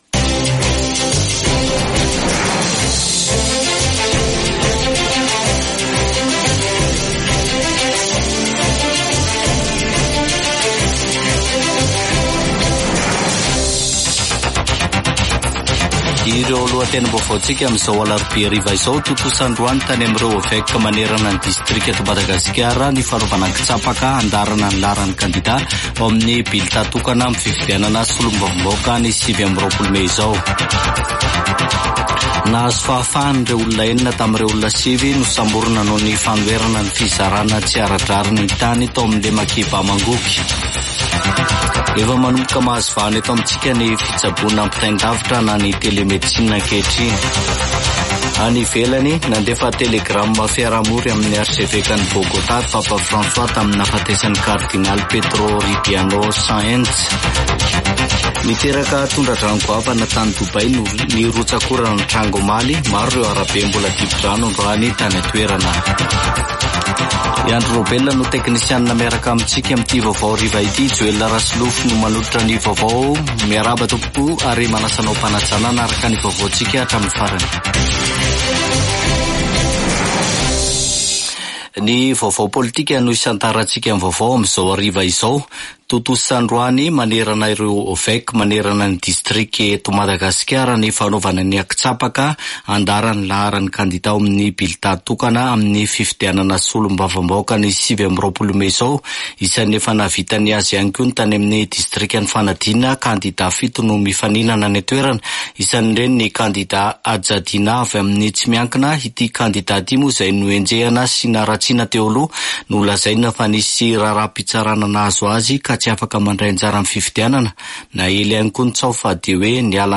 [Vaovao hariva] Alarobia 17 aprily 2024